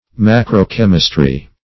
Search Result for " macro-chemistry" : The Collaborative International Dictionary of English v.0.48: Macro-chemistry \Mac`ro-chem"is*try\, n. [Macro- + chemistry.]